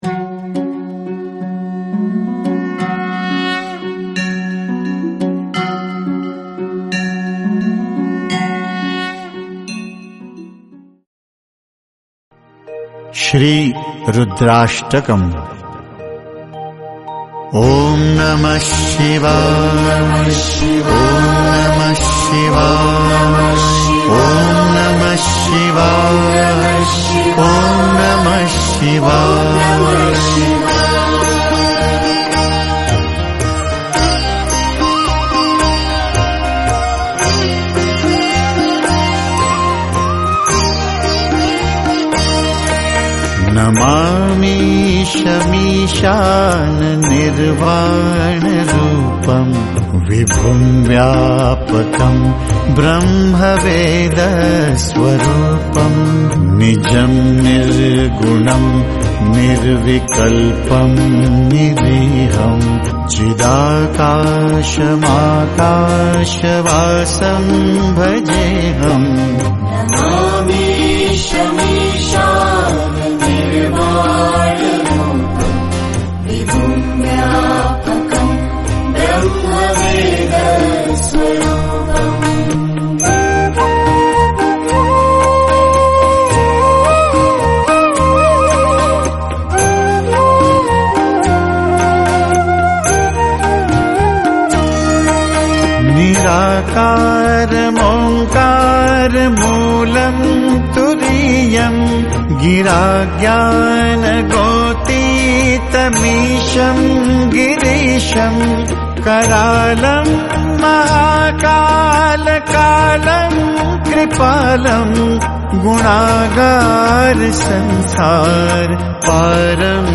Releted Files Of Devotional